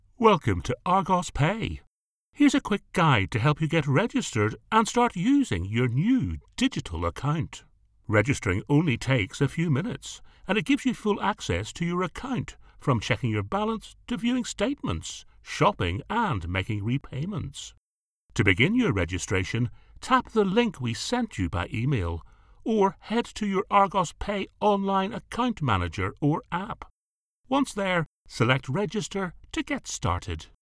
A Northern Irish Voice
E-Learning Intro
I have a Northern Irish Accent which can be street or upperclass.